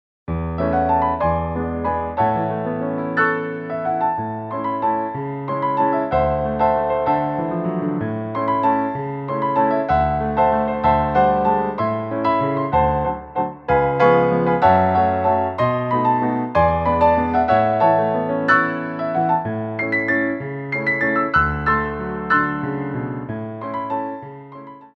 Grands Battements en Clôche
3/4 (8x8)